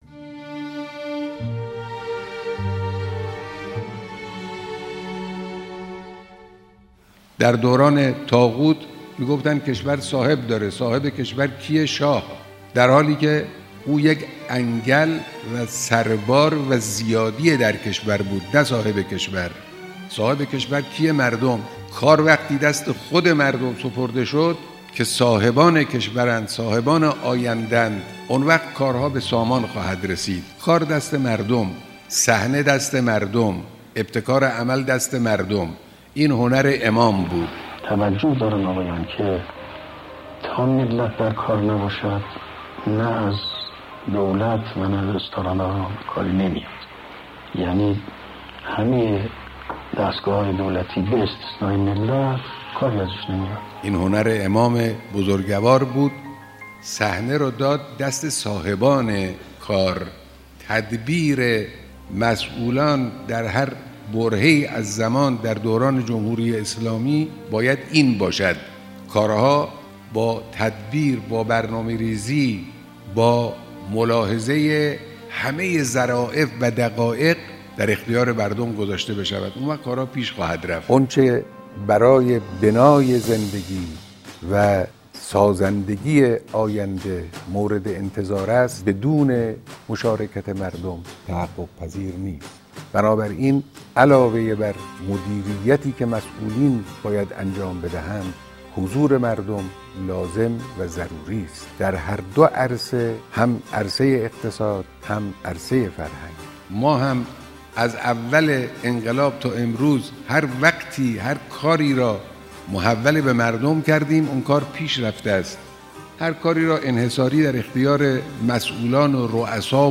معجزه ی مردم.بیانات رهبر درباره نقش مردم درپیش بردکارها واهداف.mp3